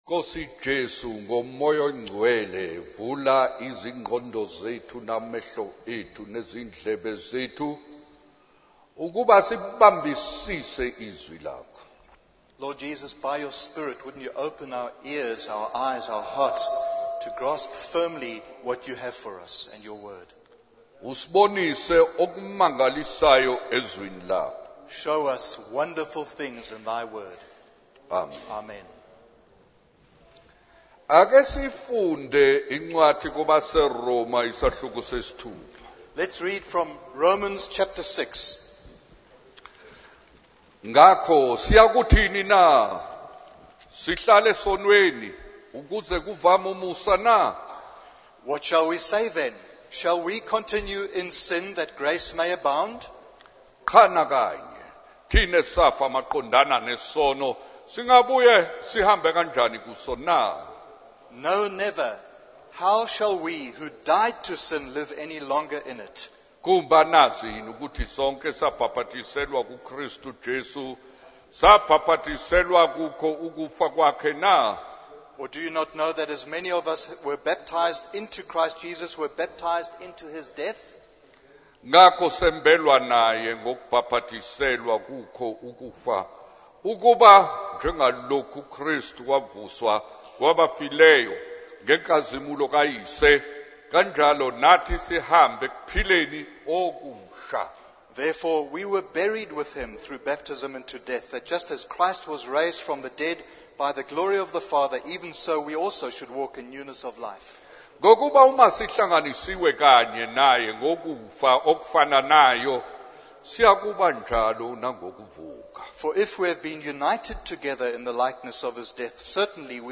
In this sermon, the preacher reflects on the allure of the world and its influence on young people. He uses a personal anecdote about a past fashion trend to illustrate the transient nature of worldly attractions. The preacher then emphasizes the significance of Jesus' crucifixion and resurrection, explaining that believers are united with Christ in his death and resurrection.